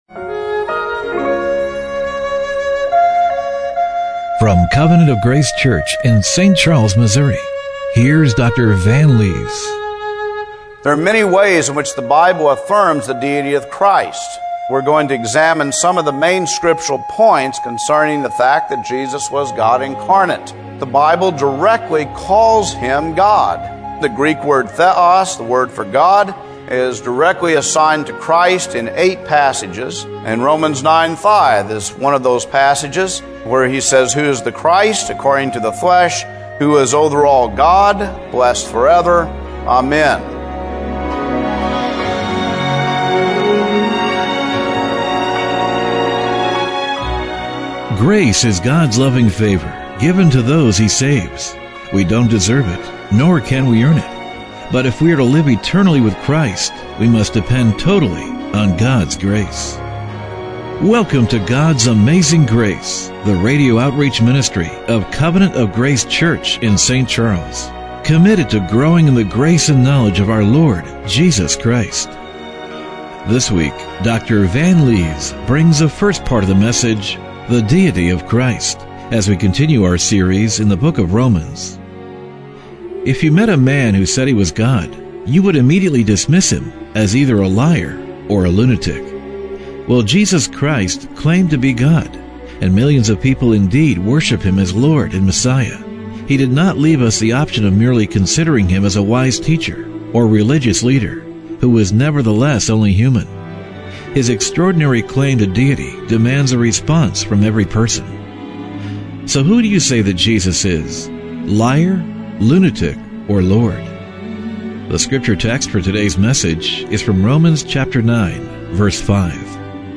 Romans 9:5 Service Type: Radio Broadcast Who do you say that Jesus is -- liar